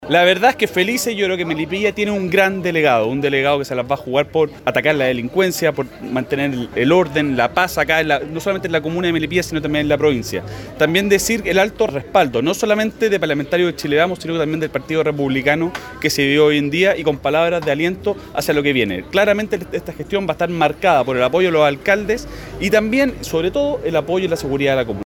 CUNA-DIPUTADO-JAIME-COLOMA-.mp3.mp3